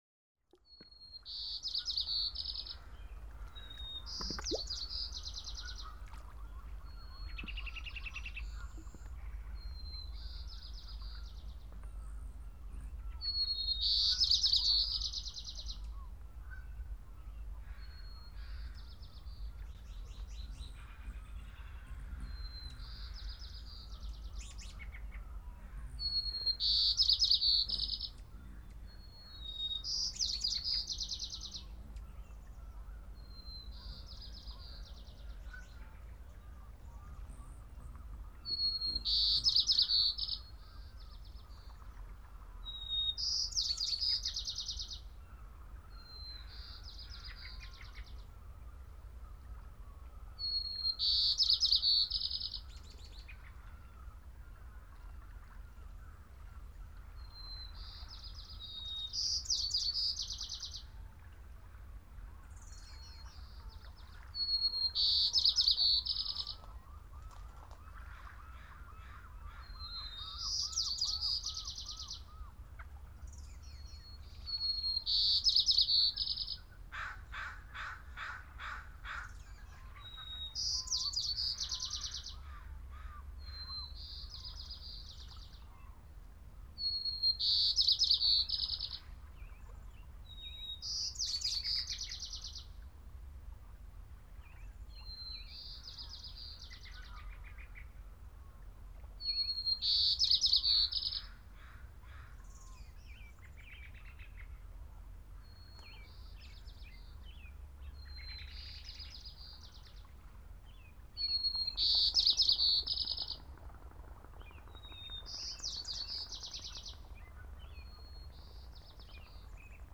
Звуки деревни
Шум летней деревни в солнечный день